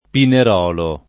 pinaiolo [ pina L0 lo ]